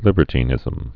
(lĭbər-tē-nĭzəm)